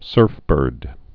(sûrfbûrd)